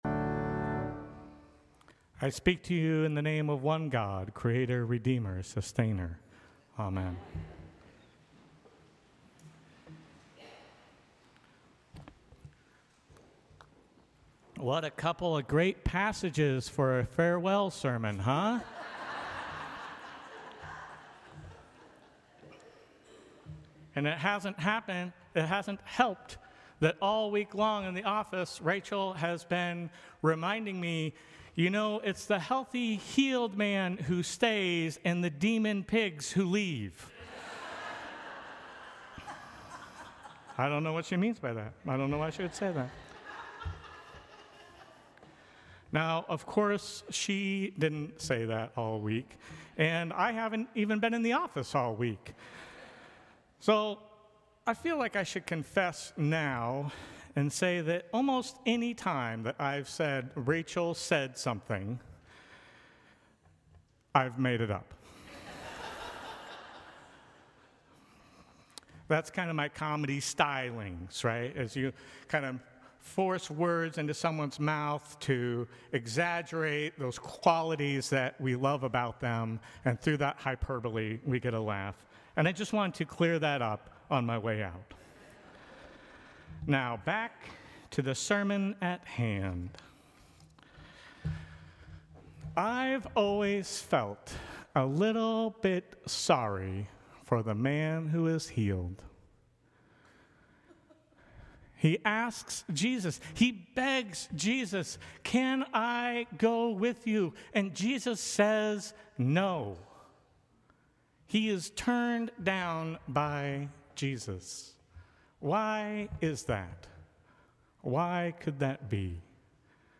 Sermons from St. Cross Episcopal Church Second Sunday after Pentecost Jun 22 2025 | 00:16:06 1x 00:00 / 00:16:06 Subscribe Share Apple Podcasts Spotify Overcast RSS Feed Share Link Embed